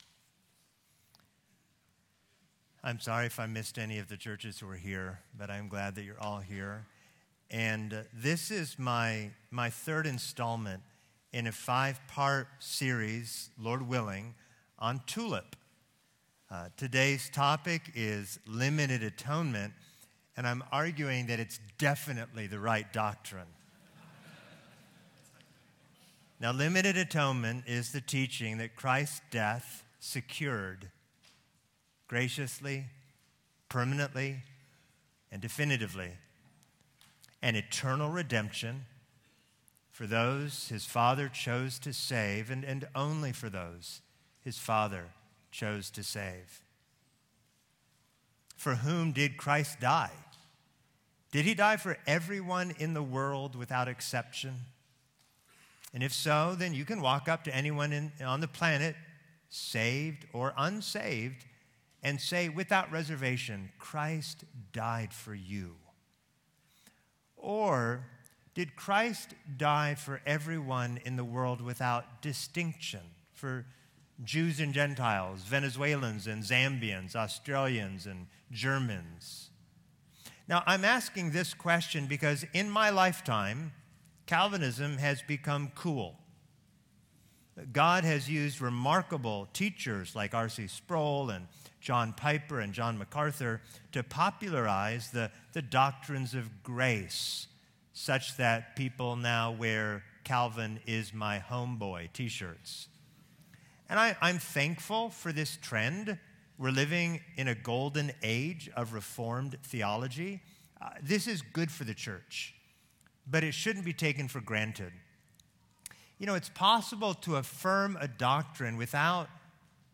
Audio recorded at Feed My Sheep Conference 2025.